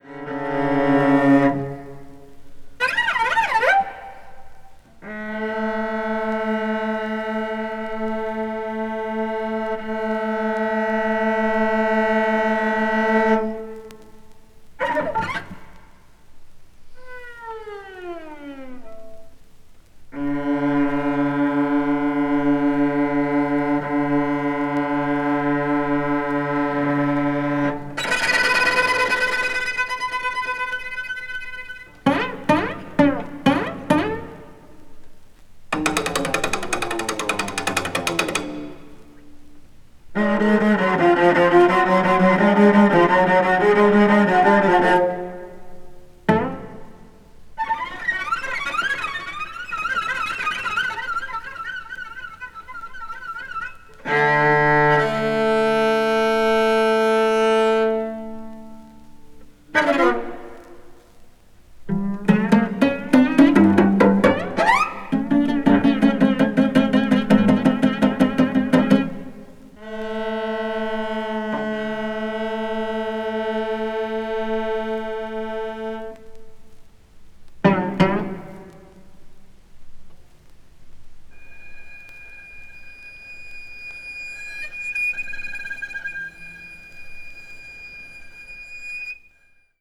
20th century   avant-garde   contemporary   post modern